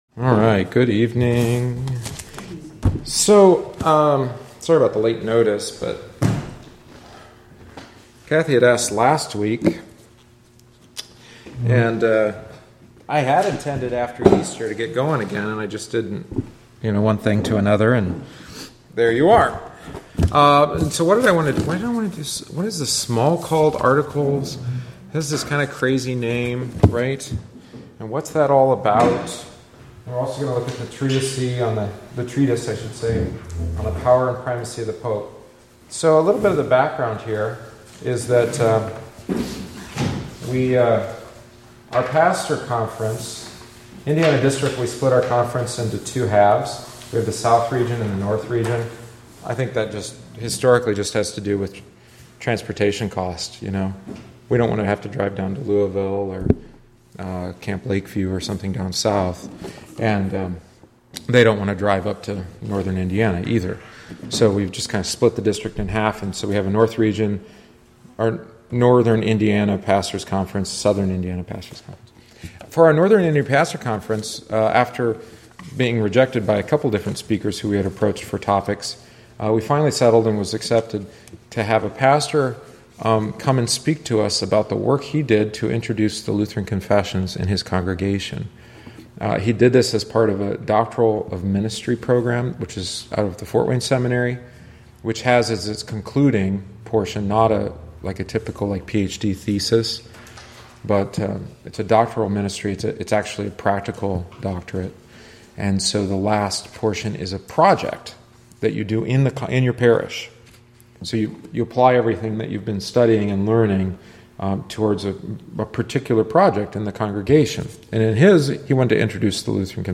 Join us each week on Wednesday after Divine Service (~7:45pm) for aÂ study of one of our Lutheran Confessions, the Smalcald Articles. Written by Martin Luther shortly before his death, it is vivid, to the point, and sometimes cantankerous.